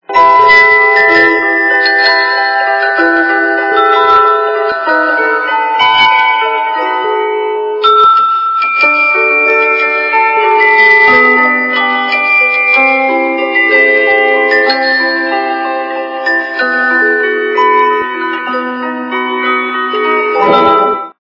Из фильмов и телепередач